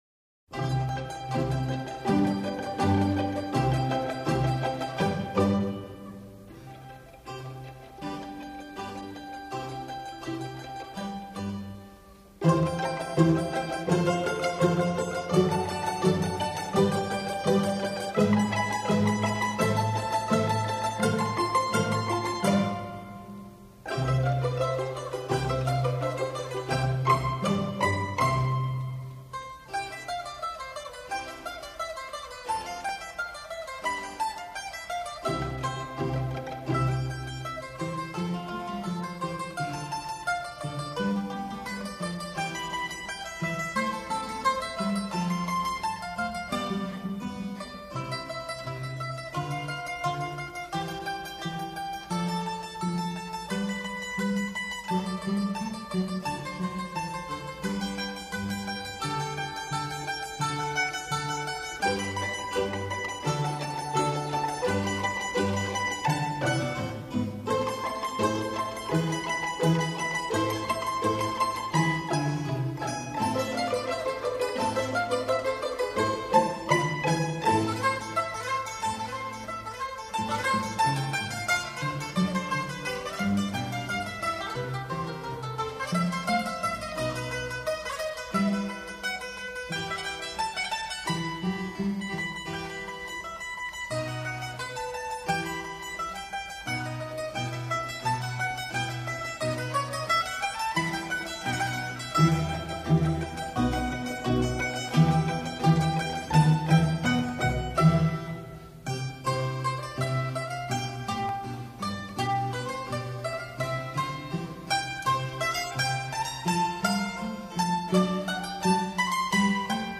classical-vivaldi-concerto-for-mandolin-strings-harpsi-1.mp3